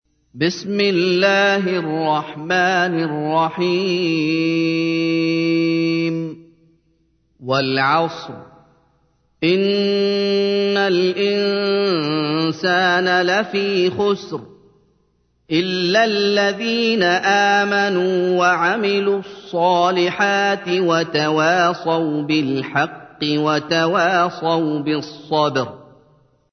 تحميل : 103. سورة العصر / القارئ محمد أيوب / القرآن الكريم / موقع يا حسين